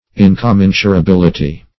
Search Result for " incommensurability" : The Collaborative International Dictionary of English v.0.48: Incommensurability \In`com*men`su*ra*bil"i*ty\, n. [Cf. F. incommensurabilit['e].]
incommensurability.mp3